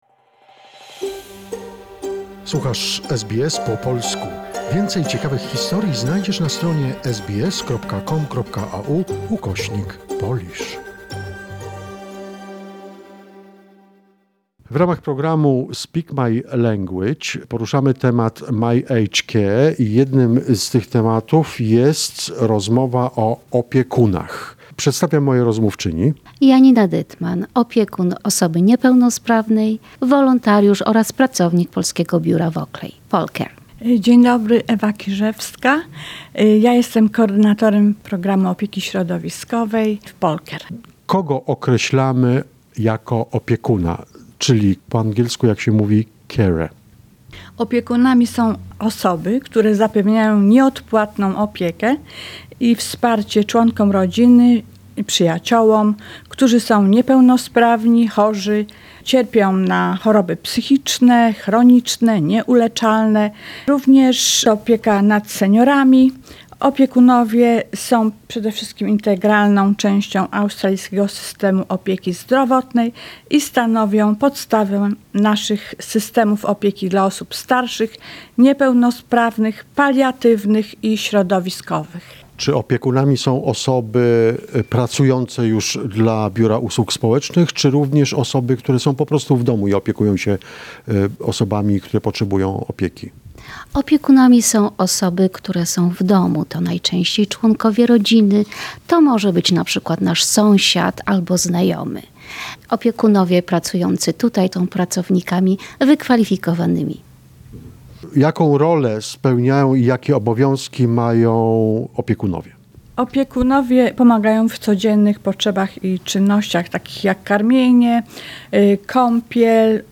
This is another conversation in the "Speak My Language" series about helping the elderly in Australia.